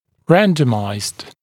[‘rændəmaɪzd][‘рэндэмайзд]рандомизированный